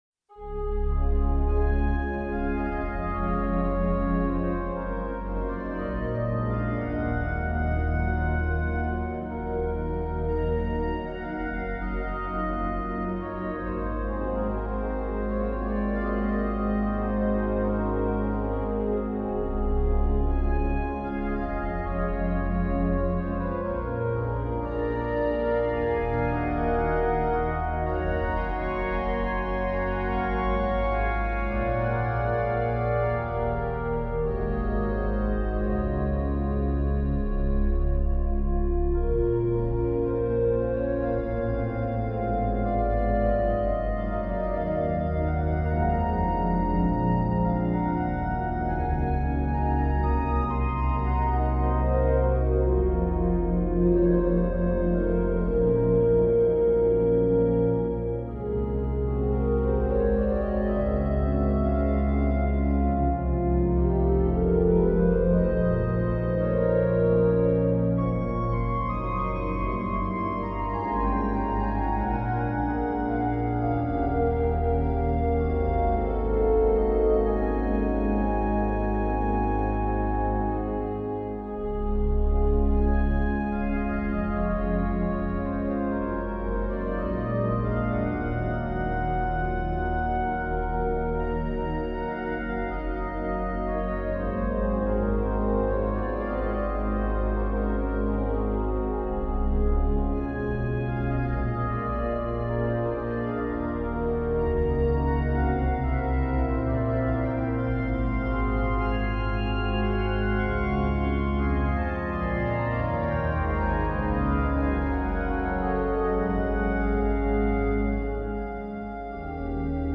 We can soon identify the ABA form: